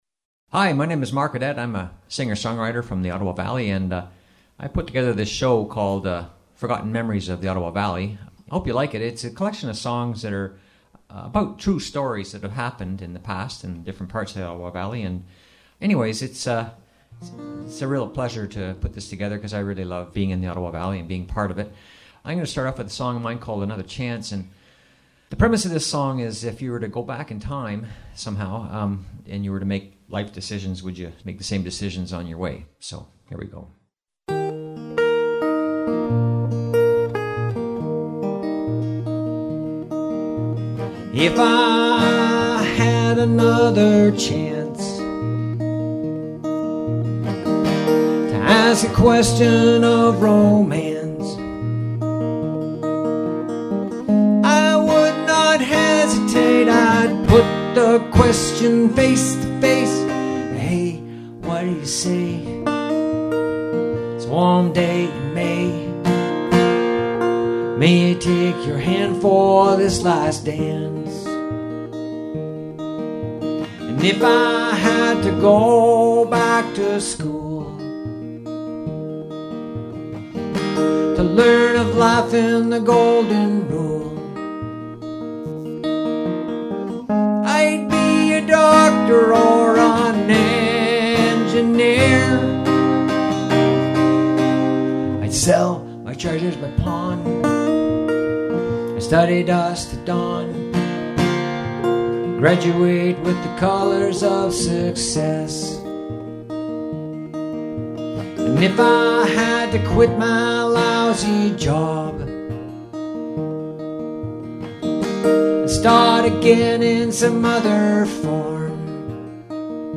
Recording Location: Bonnechere Valley, ON
story teller and singer